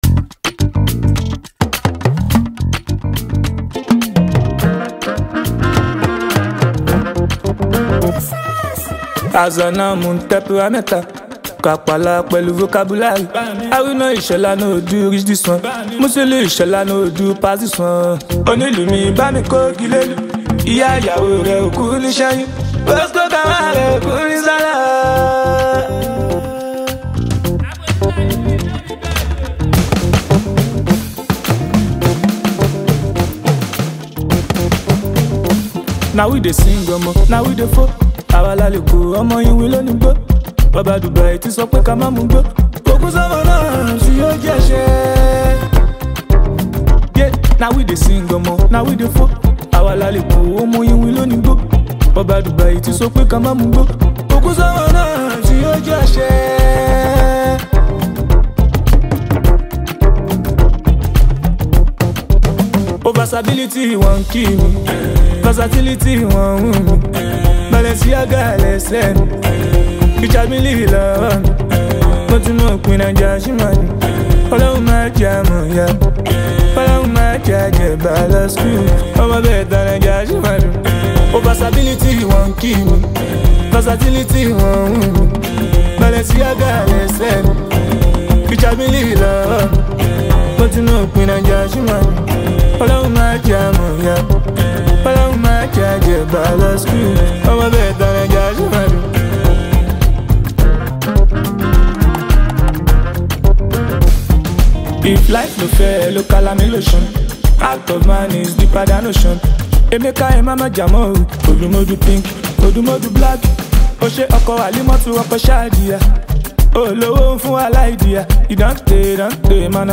soulful vocals
a rich mix of Afrobeats and mellow rhythm